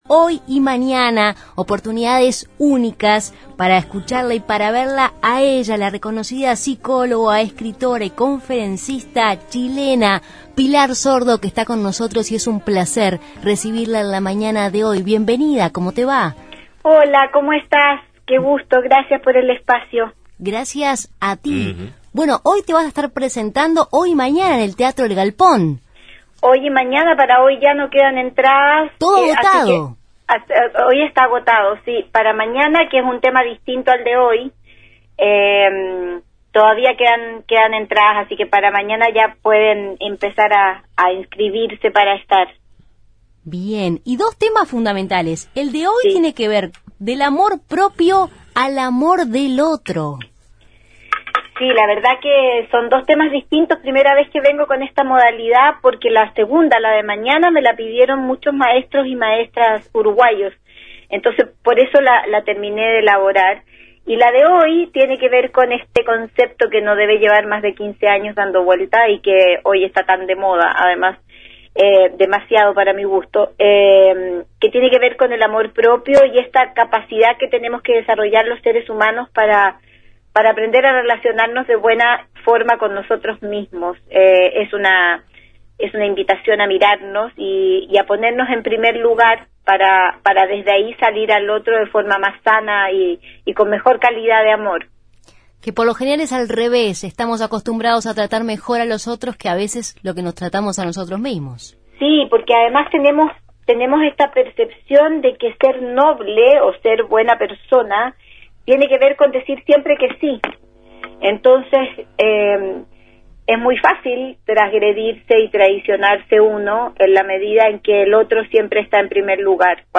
ENTREVISTA-PILAR-SORDO.mp3